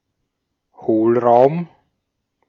Ääntäminen
Ääntäminen : IPA : /vɔɪd/ US : IPA : [vɔɪd]